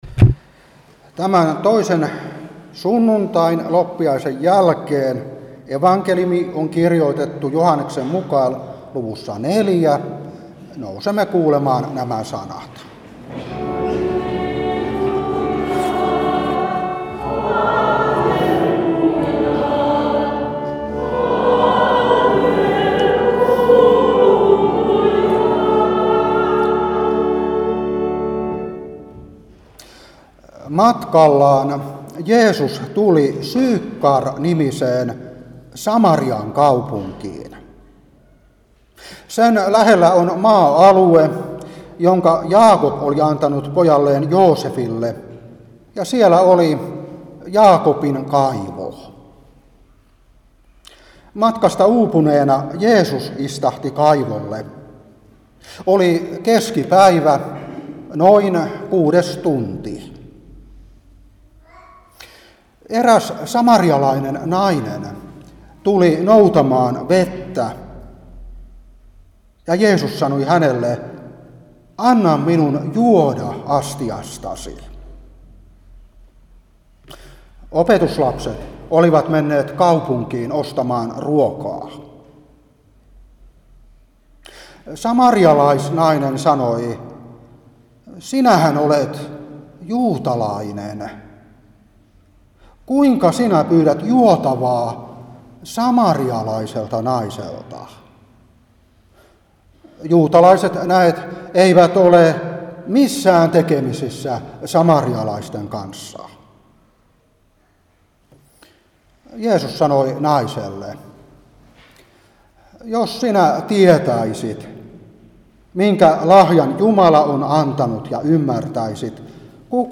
Saarna 2023-1.